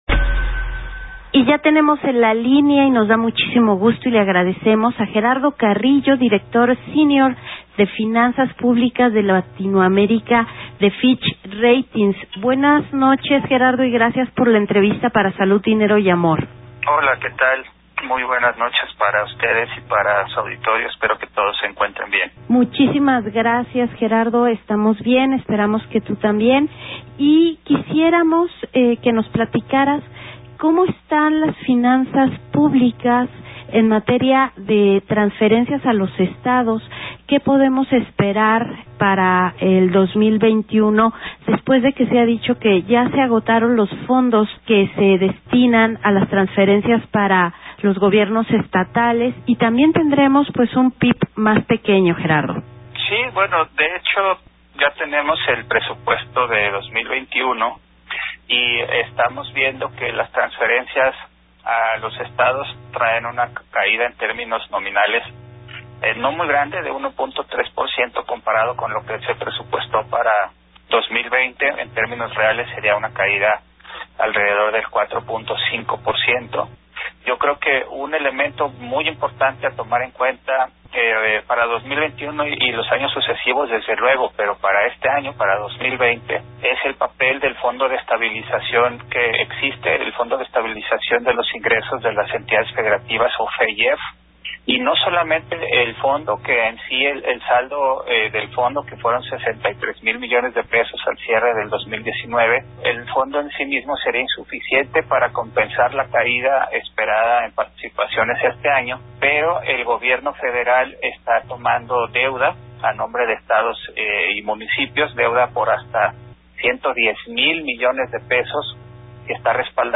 Grupo Acir, programa Salud, Dinero y Amor, entrevista